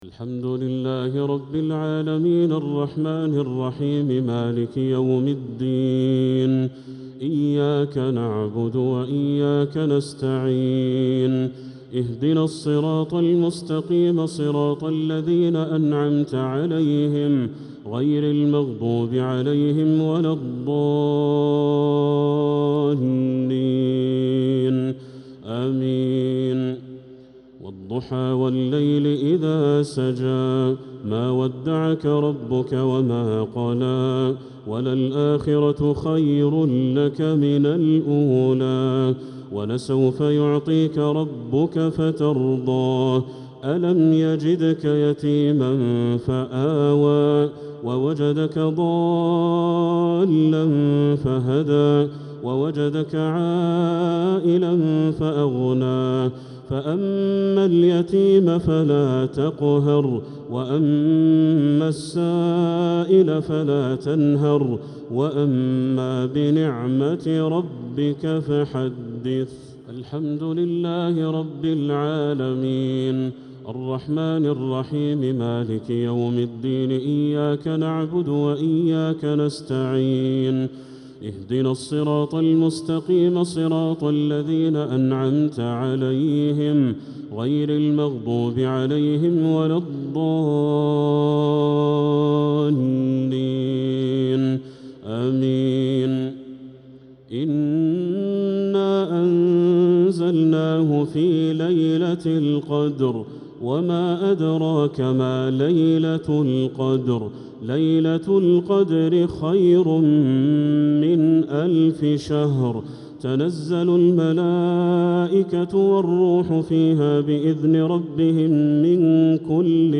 الشفع و الوتر ليلة 12 رمضان 1446هـ | Witr 12th night Ramadan 1446H > تراويح الحرم المكي عام 1446 🕋 > التراويح - تلاوات الحرمين